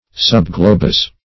Subglobose \Sub`glo*bose"\, a. Not quite globose.